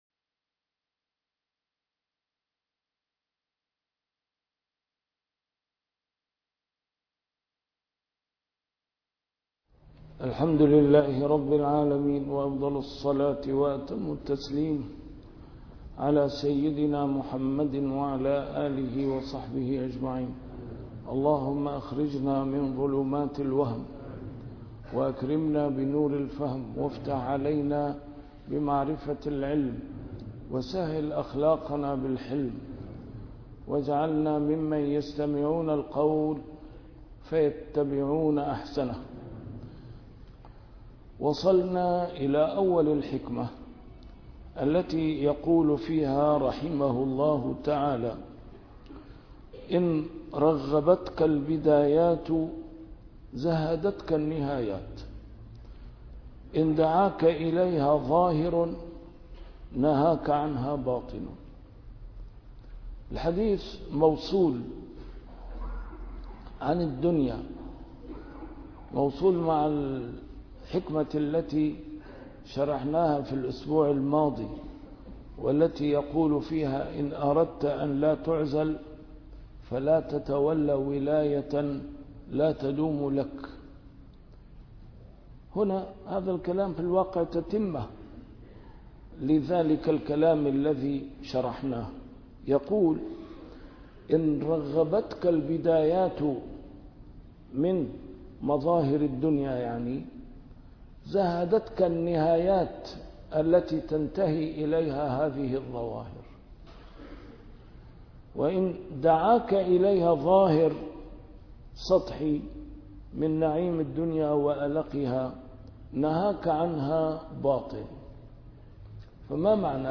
A MARTYR SCHOLAR: IMAM MUHAMMAD SAEED RAMADAN AL-BOUTI - الدروس العلمية - شرح الحكم العطائية - الدرس رقم 250 شرح الحكمة رقم 227